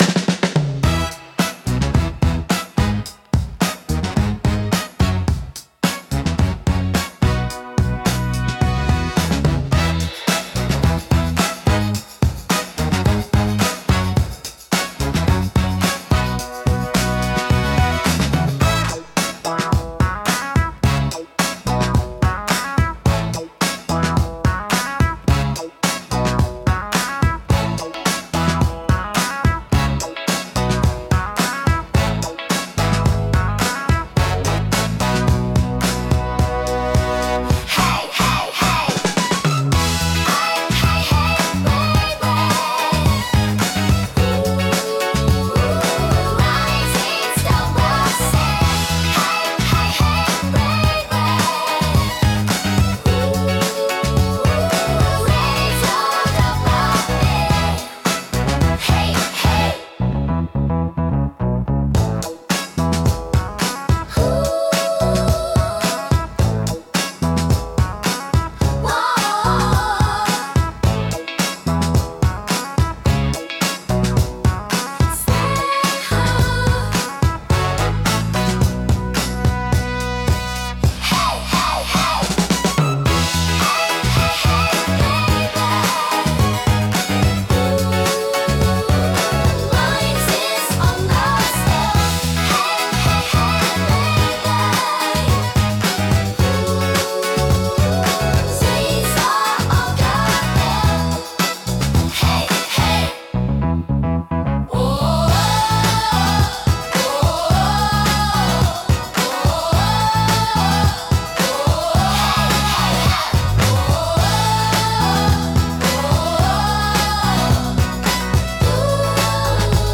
親しみやすいサウンドとポップな雰囲気、明るく感情的な楽曲が多いです。
心に残るハーモニーと温かさが魅力のジャンルです。